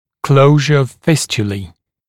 [‘kləuʒə əv ‘fɪstjəliː][‘клоужэ ов ‘фистйэли:]закрытие фистул